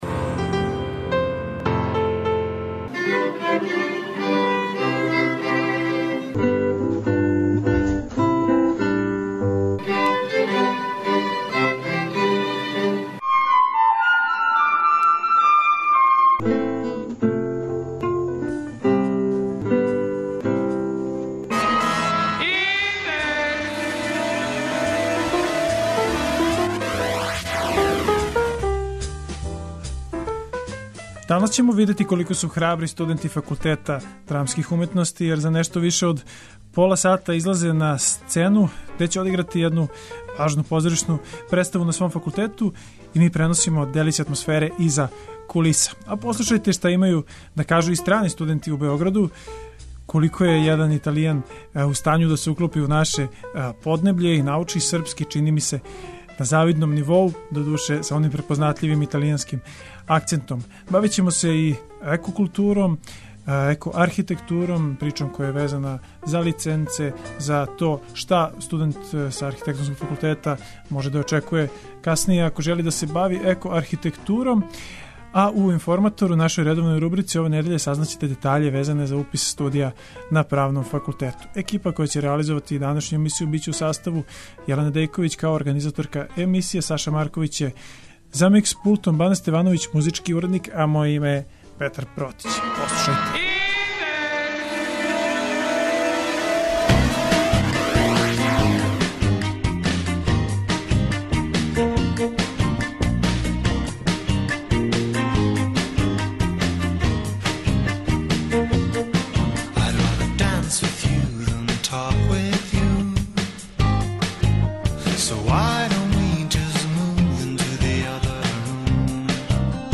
У тренуцима док траје емисија, на Факултету драмских уметности је у току веома занимљива студентска представа. Наш репортер је одмах иза кулиса и преноси атмосферу, разговара са актерима представе и покушава да, путем радија, дочара како изгледа живо стварање позоришног комада.